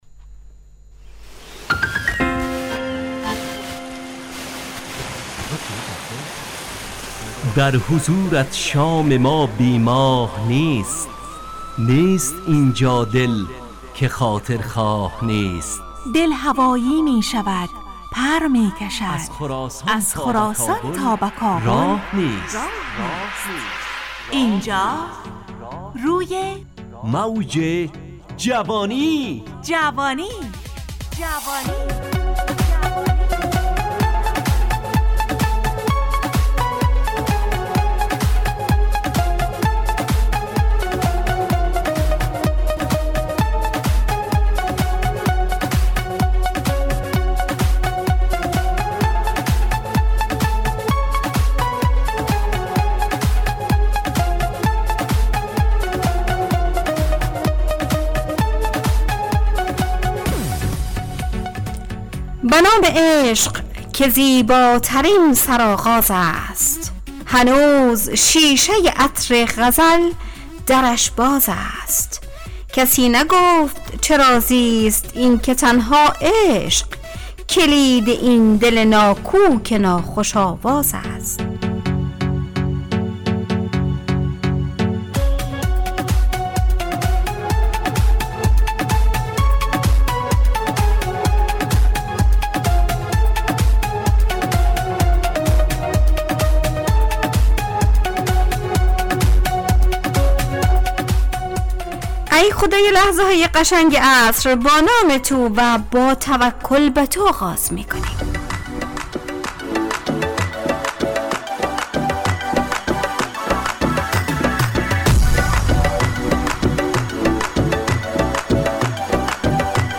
همراه با ترانه و موسیقی مدت برنامه 70 دقیقه . بحث محوری این هفته (صبر) تهیه کننده